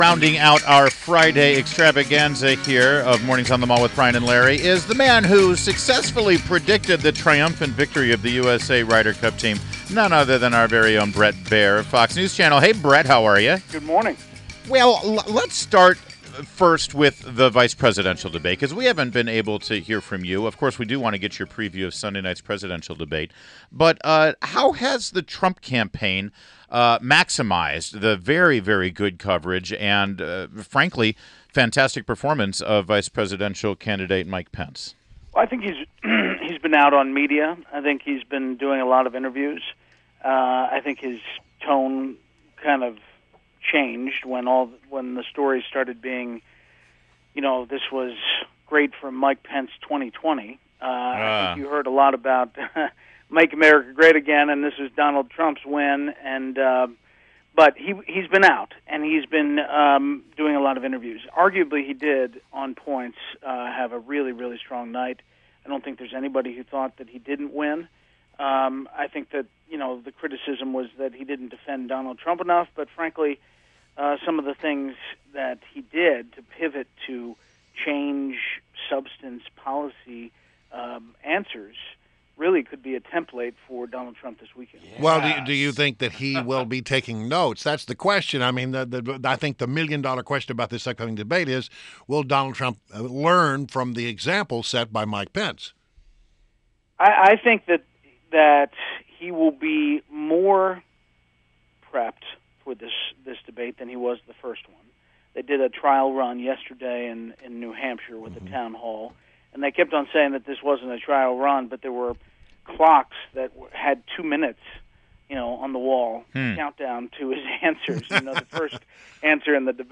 WMAL Interview - BRET BAIER - 10.07.16